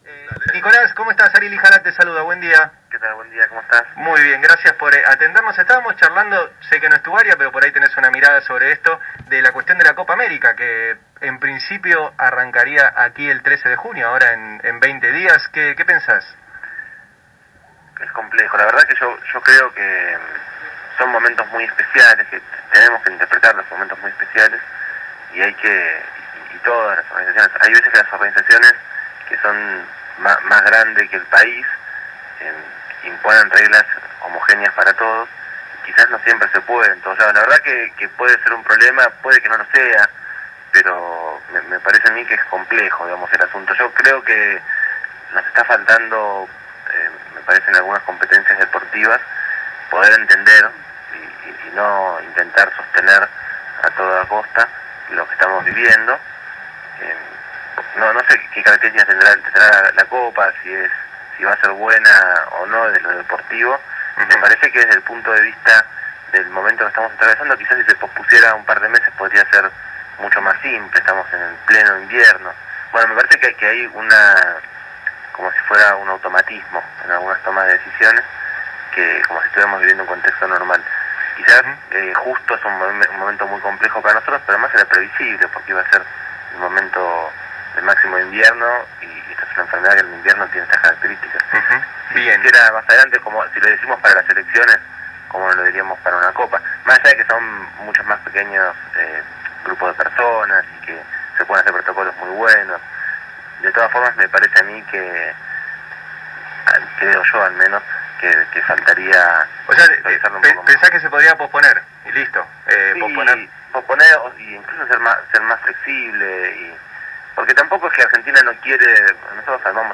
Ahora, Nicolás Kreplak se refirió al tema, indicando que “Se podría posponer. Nosotros amamos el fútbol. No es que no queremos jugar la Copa o que se haga en el país. Pero no es el mejor momento”, según declaraciones dadas a conocer por radio El Destape.
de la Provincia de Buenos Aires, Nicolás Kreplak